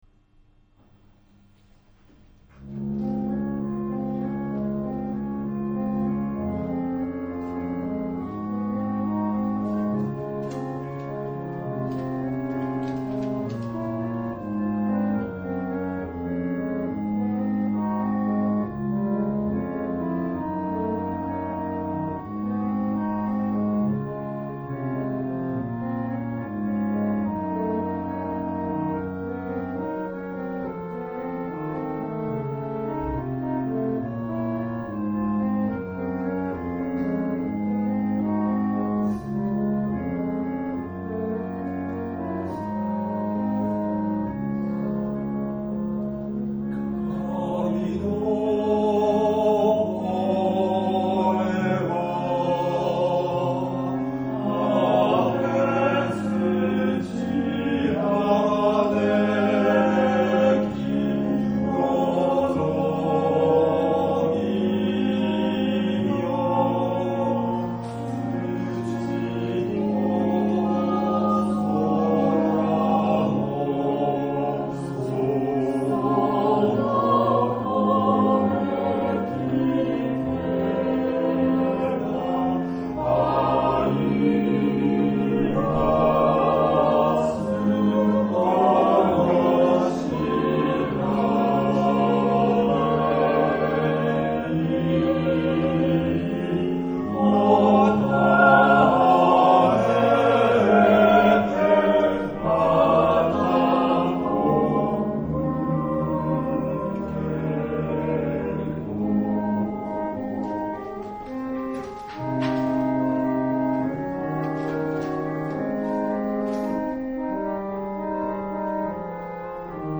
Kashiwa Shalom Church Choir